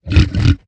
assets / minecraft / sounds / mob / hoglin / idle7.ogg